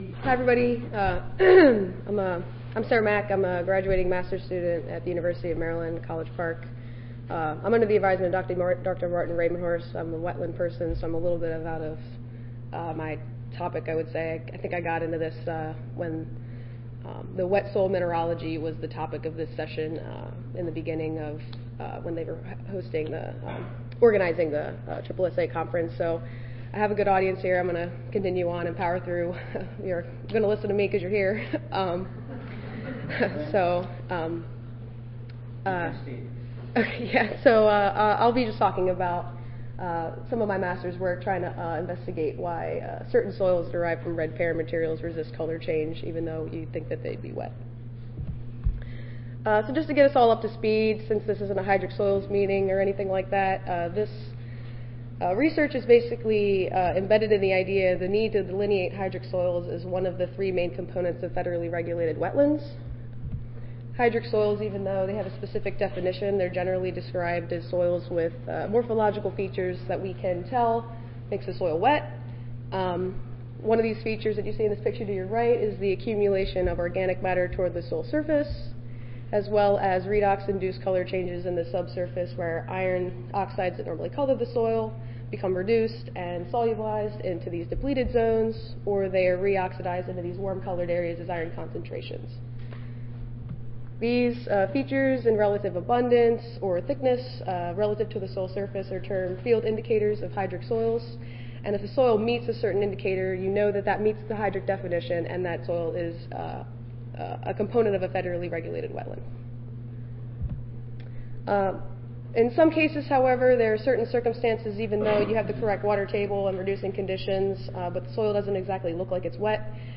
Session: The Future of Soil Horizon Research (includes student competition) (ASA, CSSA and SSSA International Annual Meetings)
US Army Corps of Engineers Audio File Recorded Presentation